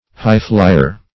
Highflier \High"fli`er\, n.